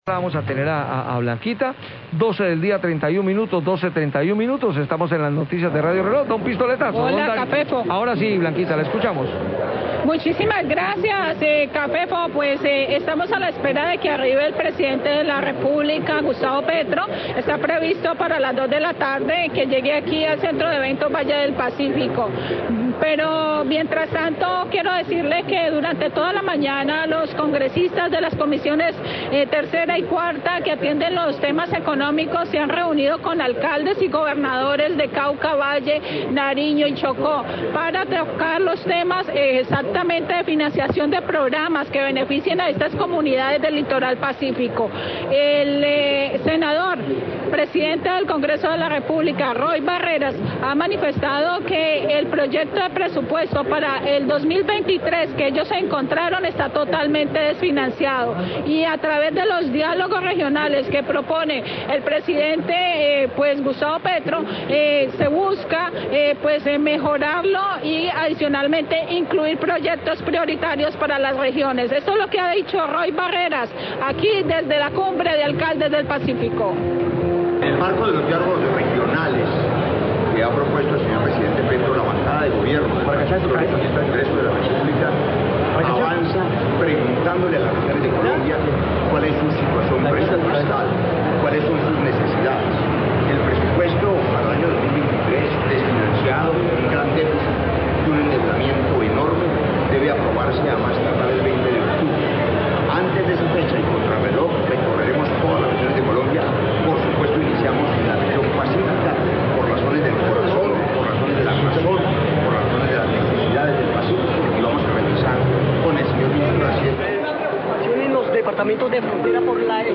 Radio
Senador Roy Barreras, durante su visita a Cali para la Cumbre de alcaldes del pacífico, manifestó que el proyecto de presupuesto para el 2023 se encuentra desfinanciado. Indicó que se debe mejorar el presupuesto he incluir proyectos prioritarios para las regiones.